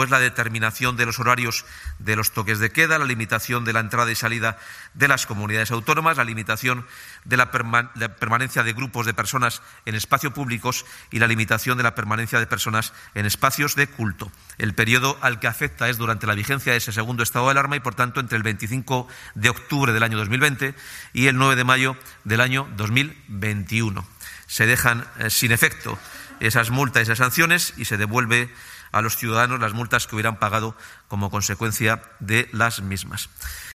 El portavoz de la Junta, Carlos Fernández Carriedo, ha explicado que hasta la constitución de Las Cortes el próximo 10 de marzo se “garantizará el trabajo ordinario” de la Junta.
El portavoz de la Junta ha informado que tipo de multas quedan sin efecto (Toques de queda, cierre perimetral o permanecer en grupos multitudinarios) (ESCUCHAR AUDIO).